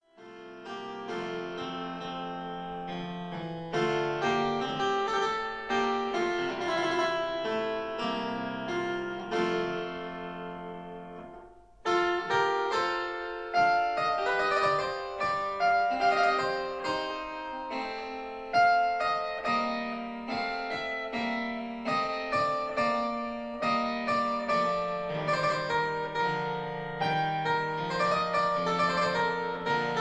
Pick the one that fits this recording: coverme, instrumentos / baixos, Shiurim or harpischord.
harpischord